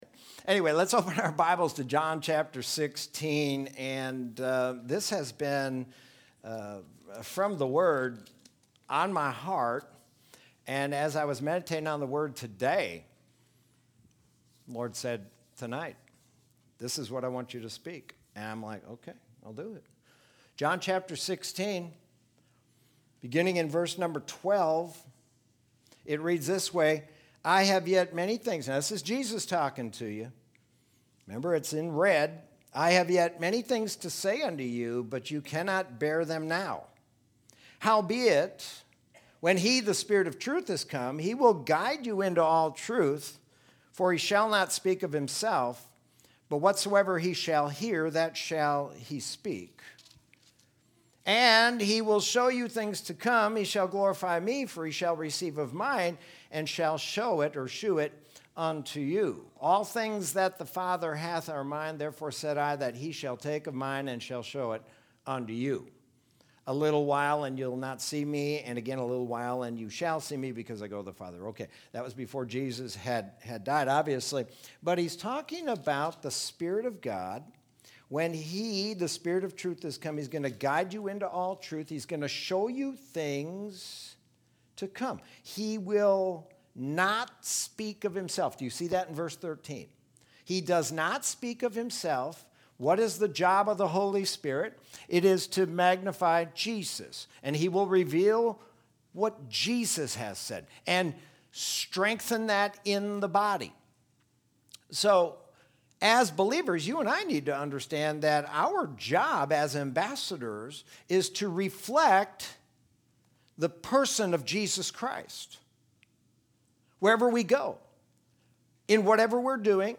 Sermon from Wednesday, August 5th, 2020.